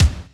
drumOn.wav